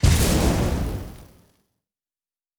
Fire Spelll 28.wav